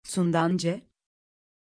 Aussprache von Sundance
pronunciation-sundance-tr.mp3